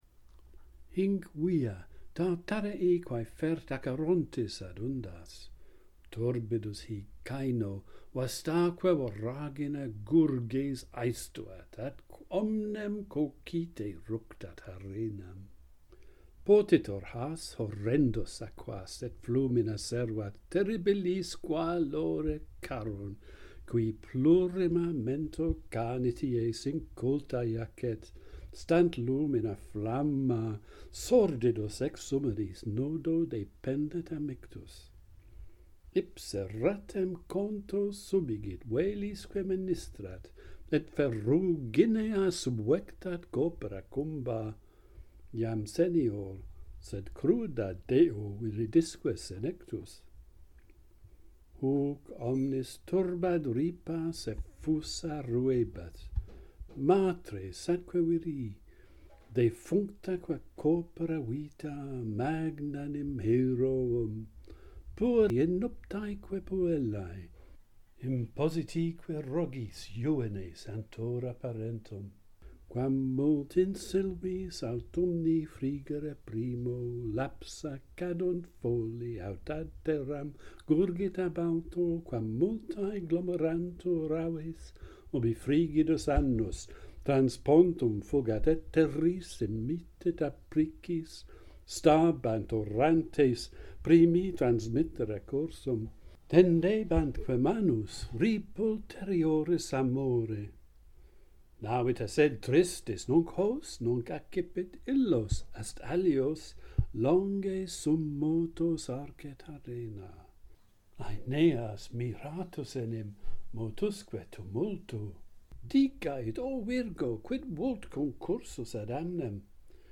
Charon, the ferryman - Pantheon Poets | Latin Poetry Recited and Translated